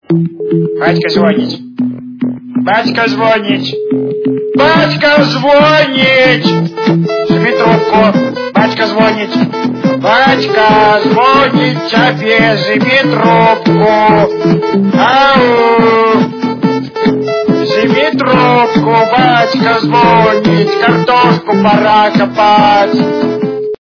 » Звуки » Люди фразы » Звонок от Отца - Батько звонит, сними трубку
При прослушивании Звонок от Отца - Батько звонит, сними трубку качество понижено и присутствуют гудки.